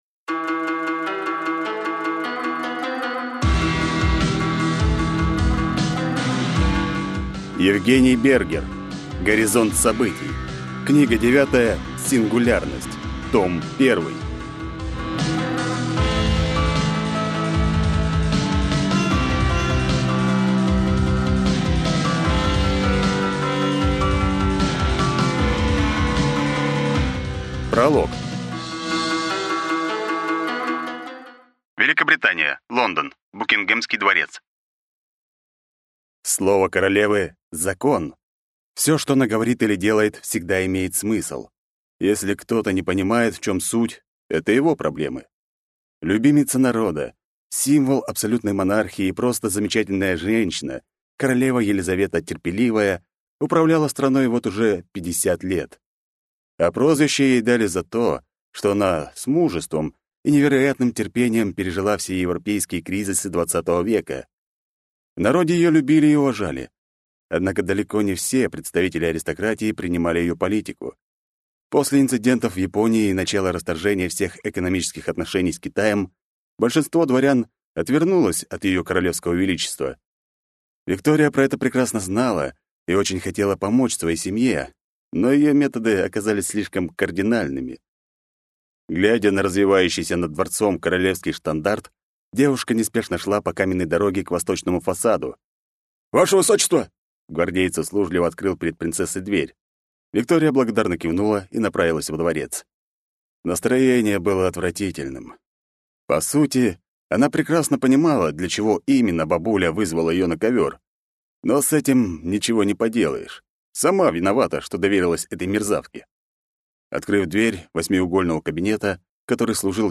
Аудиокнига Горизонт событий. Книга 9. Сингулярность. Том 1 | Библиотека аудиокниг